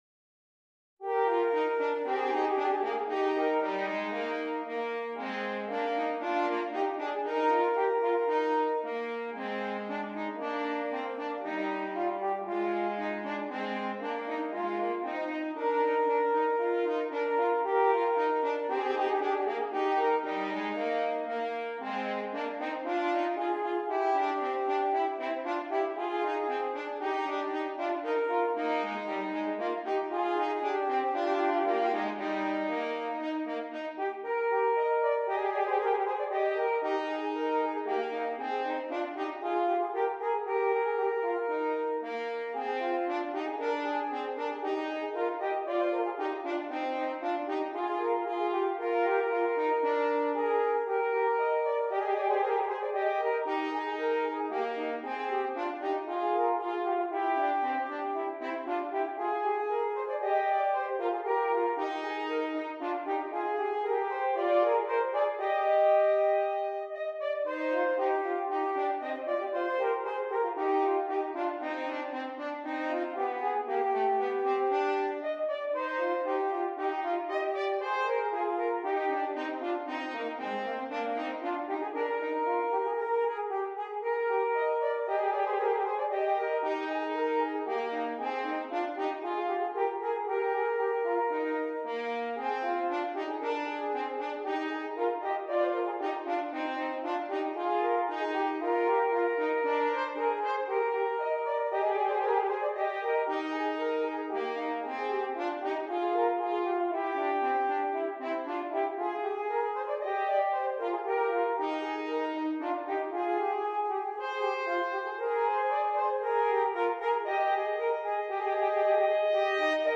2 F Horns